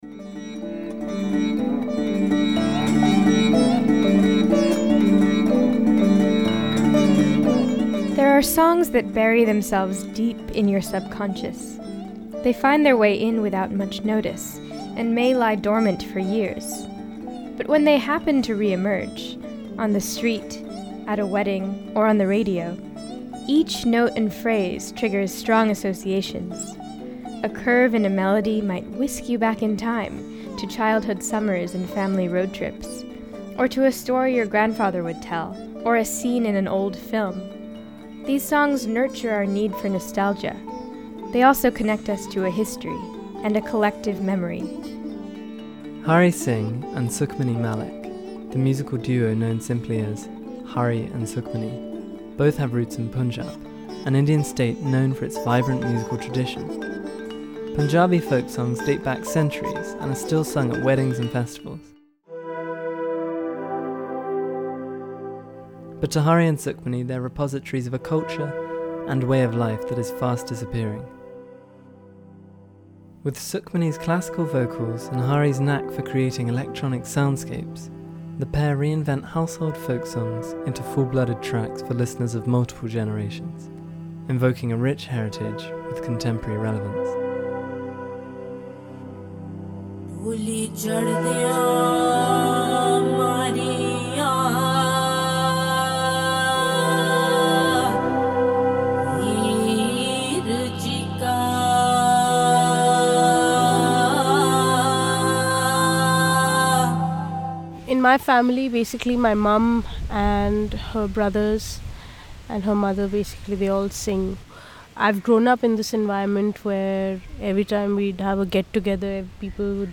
We found shade in the Hauz Khas Fort for this conversation on the dominance of Bollywood music, the beauty of intergenerational audiences, and the value of reimagining tradition.